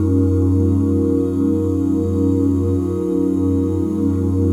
OOH F#MAJ9.wav